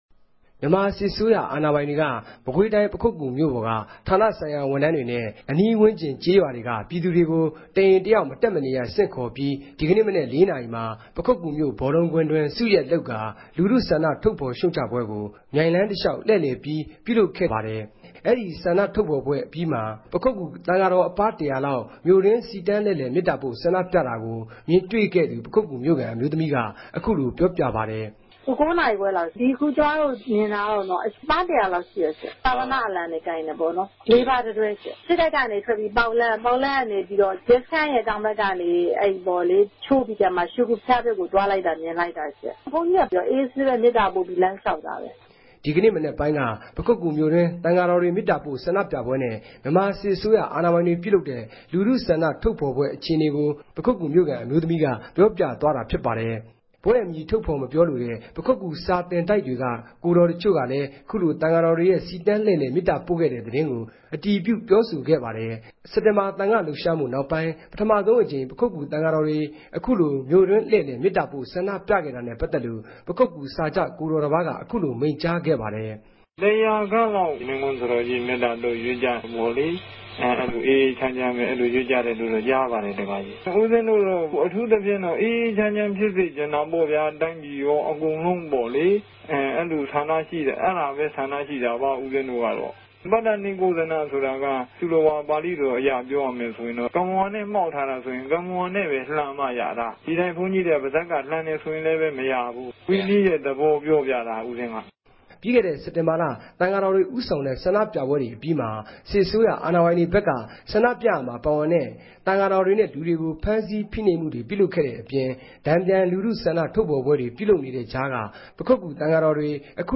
ဆ္ဋိံူပပြဲကို ူမင်တြေႛခဲ့သူ ပခုက္က္ဘြမိြႛခံ အမဵိြးသမီးနဲႛ ပခုက္ကြ စာခဵ ဆရာတော်တပၝးကို